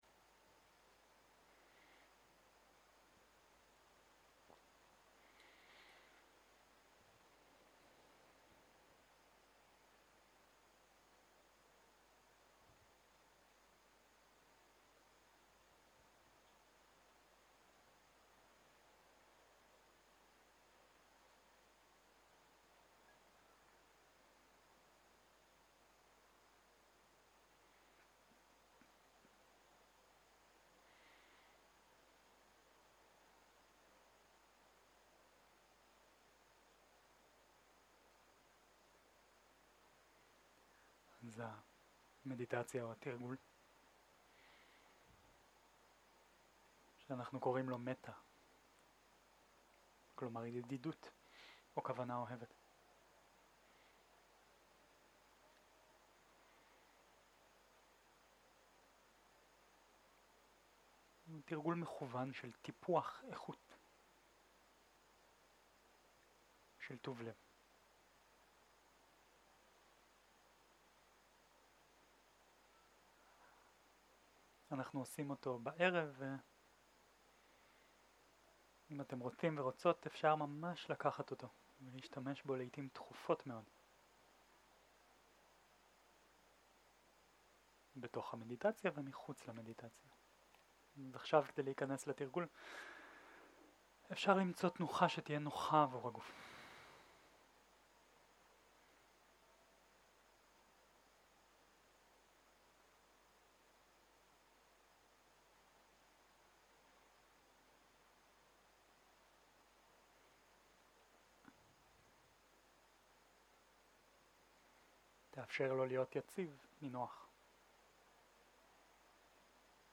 מדיטציה מונחית